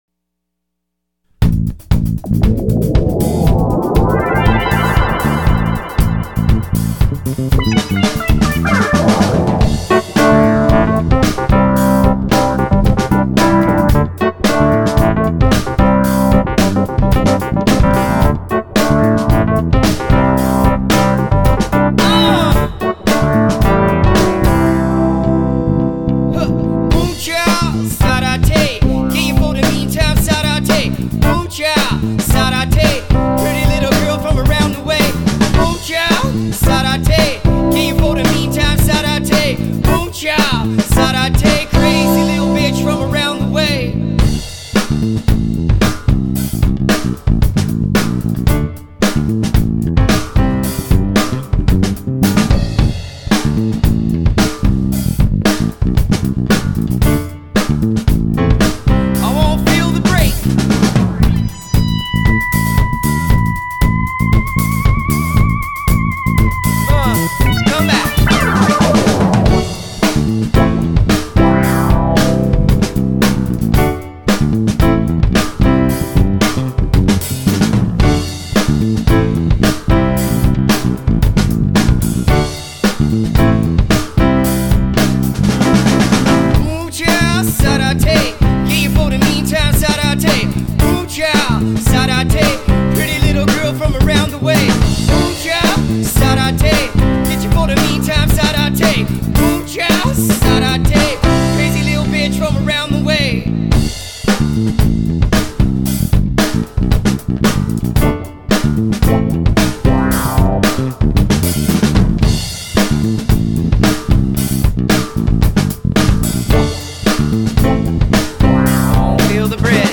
this one was part of our live repertoire in the early days.